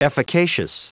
Prononciation du mot efficacious en anglais (fichier audio)
Prononciation du mot : efficacious